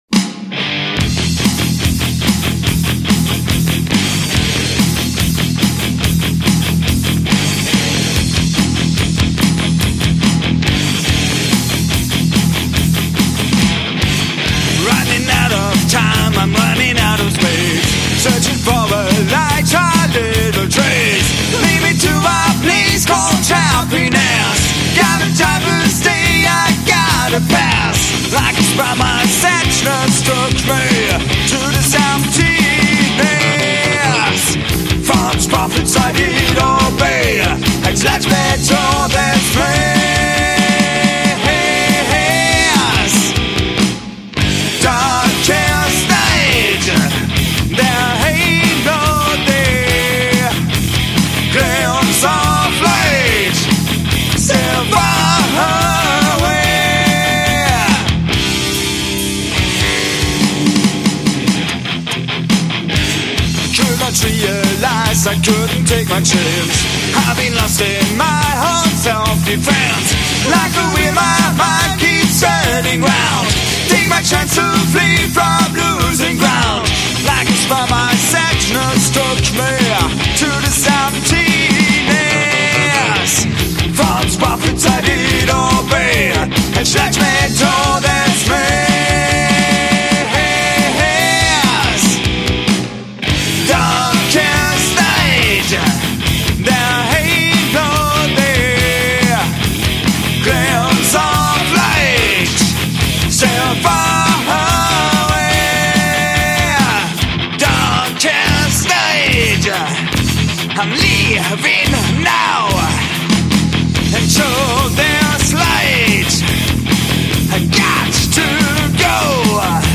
im Gerna Studio, Bochum (Deutschland)